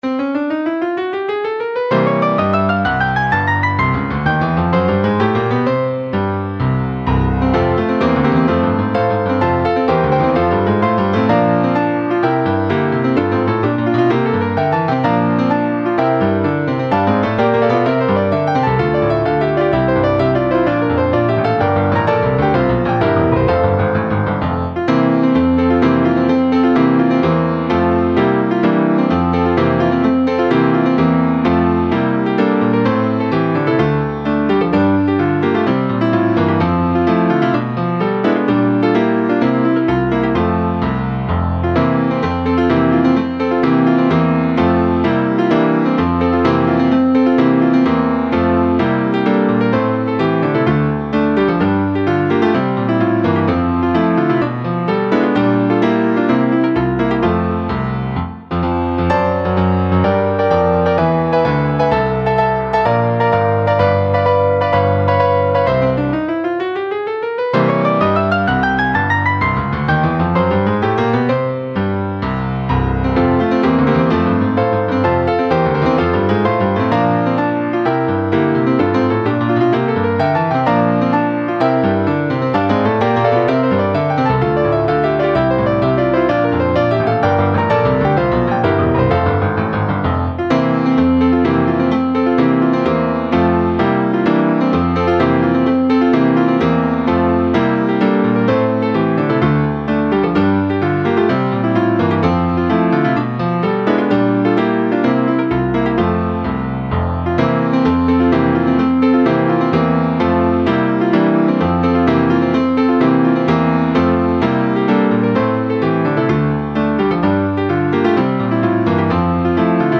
(Base Mp3)
la principessa lind base.mp3